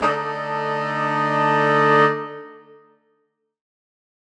Index of /musicradar/undercover-samples/Horn Swells/D
UC_HornSwell_Dmin9.wav